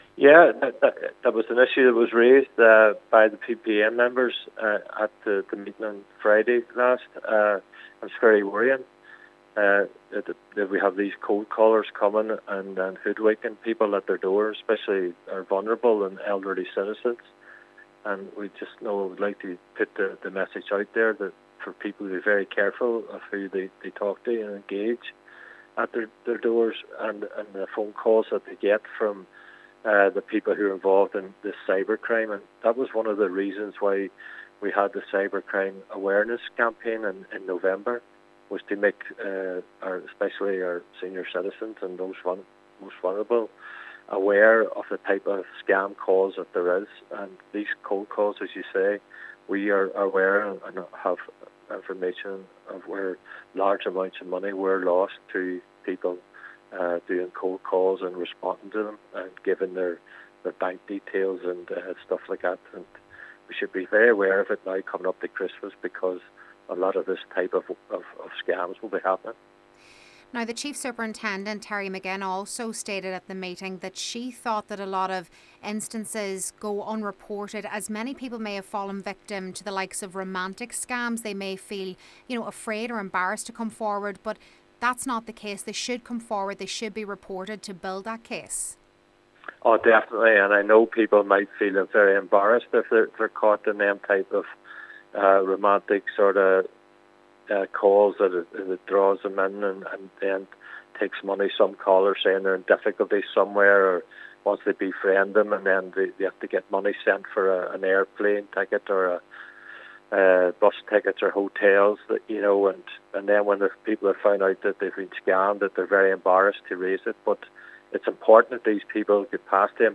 Chair of the Donegal Joint Policing Committee Cllr Gerry McMonagle is encouraging people to report these type of crimes: